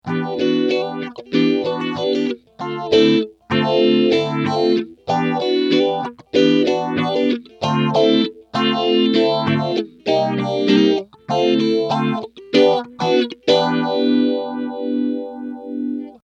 Rate 0%, Recycle 50% (227k)
Clips were recorded:  Pretty guitar - effect - Bulldog Cab Sim - sound card